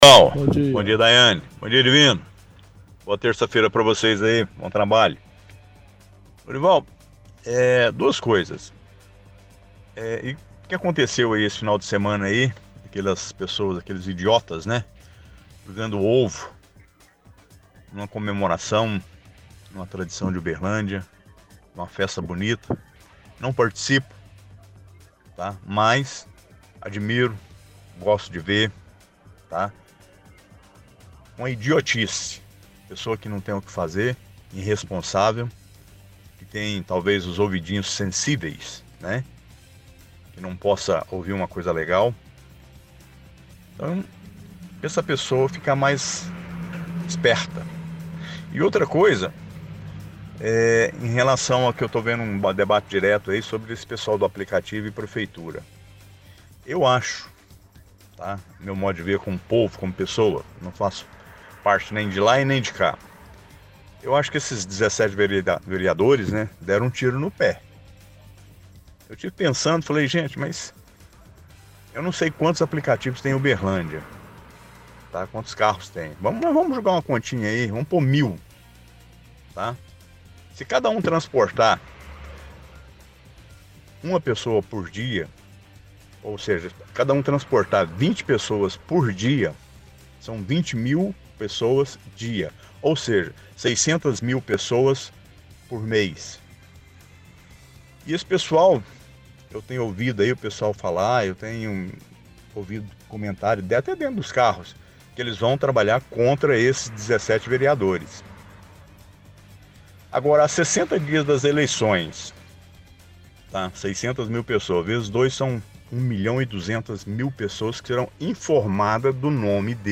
– Ouvinte fala sobre o ataque com ovos aos congadeiros, diz que admira e gosta de ver, condena as pessoas que fizeram isso.